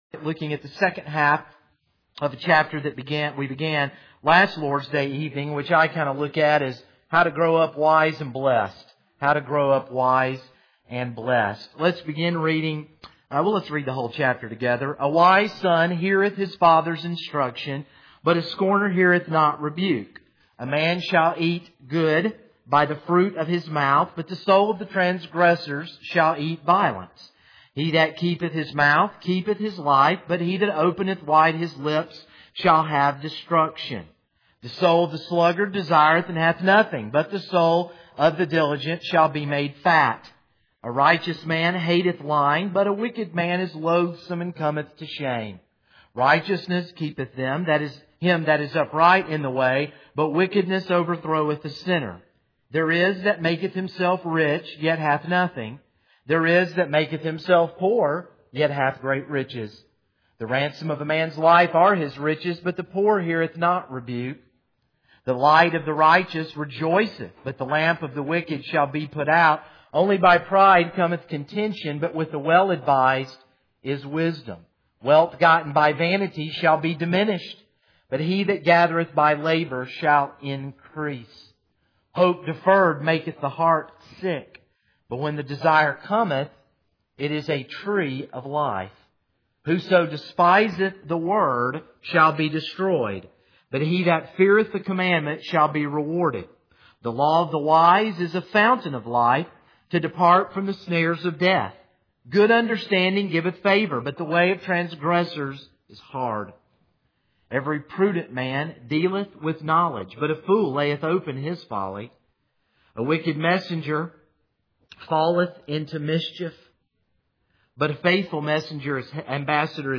This is a sermon on Proverbs 13:12-25.